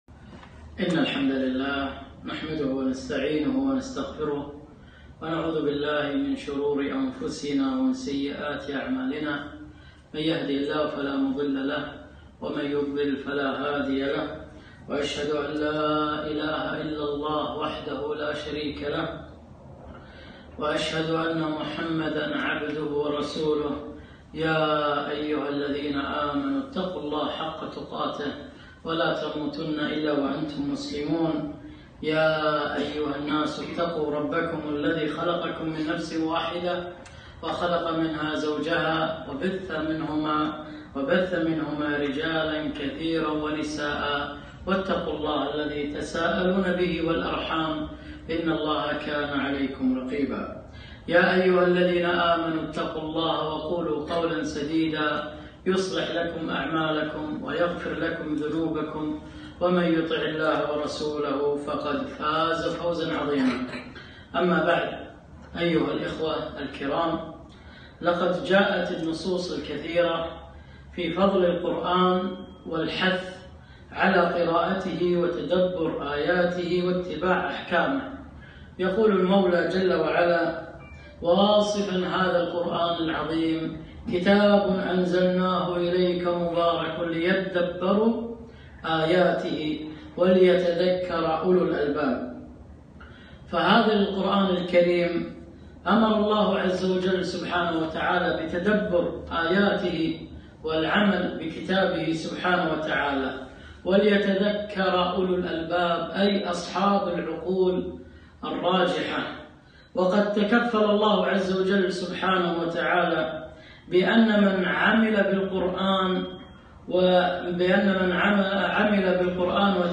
محاضرة - فضل القرآن وأهله